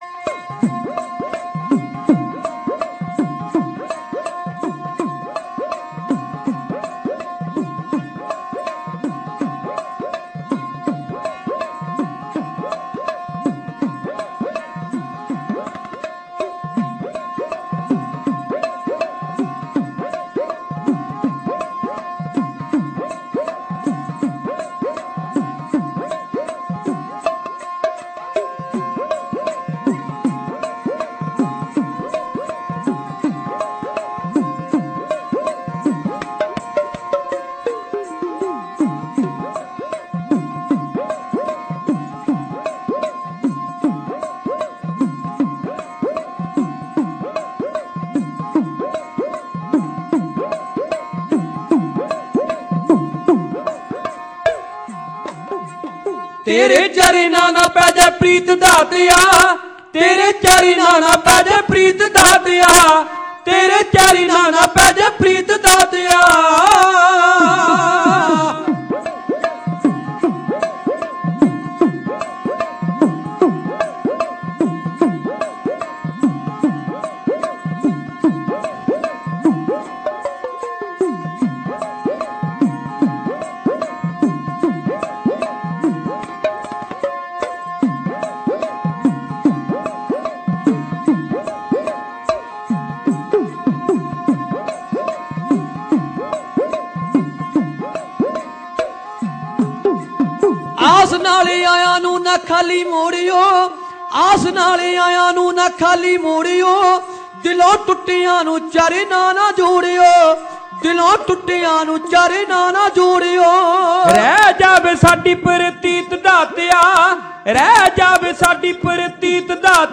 Genre: Dhadi Vaara